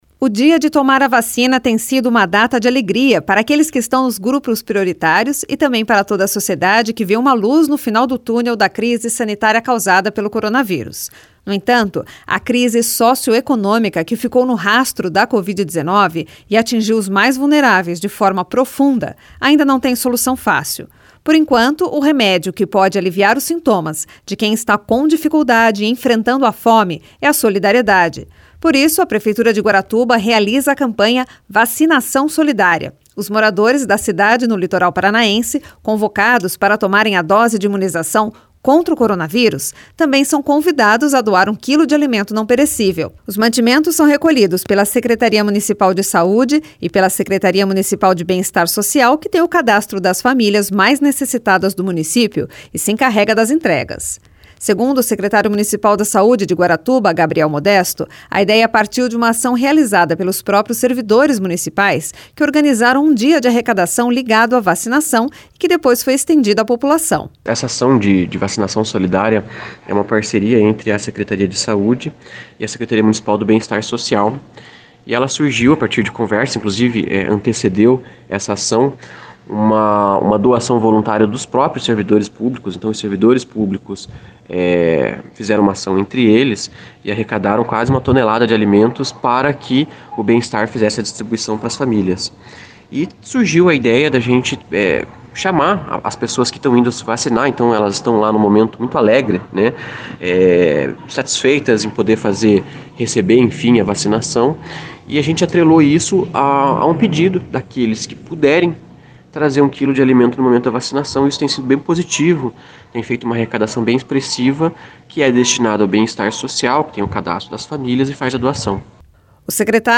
Segundo o secretário municipal da Saúde de Guaratuba, Gabriel Modesto, a ideia partiu de uma ação realizada pelos próprios servidores municipais, que organizaram um dia de arrecadação ligado à vacinação, que depois foi estendida à população.
Repórter